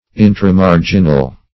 Intramarginal \In`tra*mar"gin*al\, a. Situated within the margin.